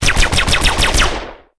machine1.ogg